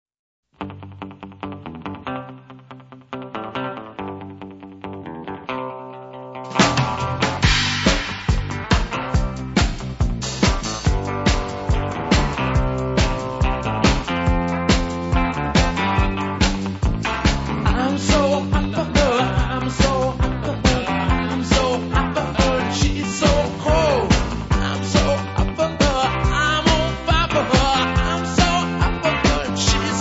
• rock